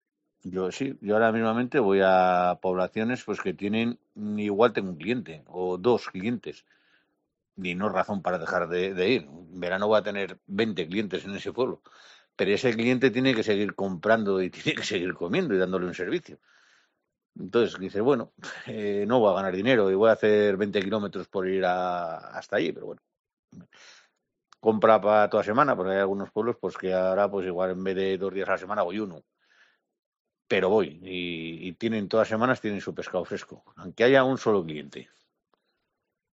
Un pescadero